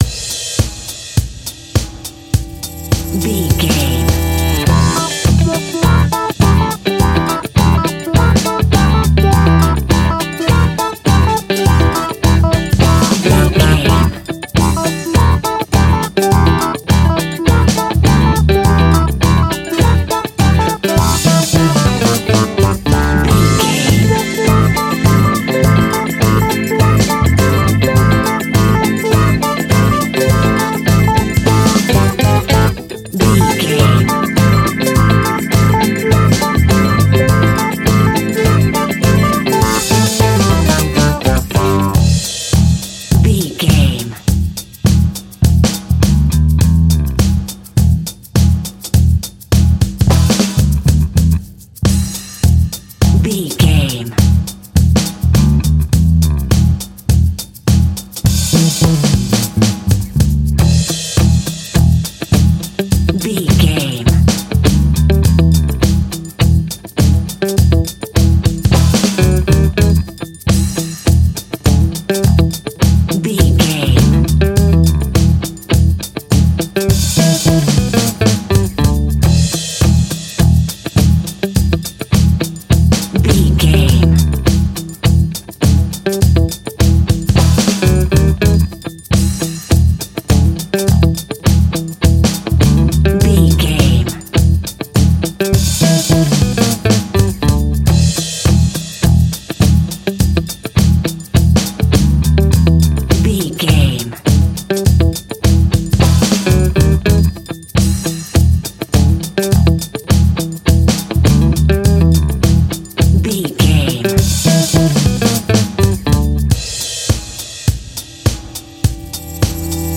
Aeolian/Minor
groovy
lively
electric guitar
electric organ
drums
bass guitar
saxophone
percussion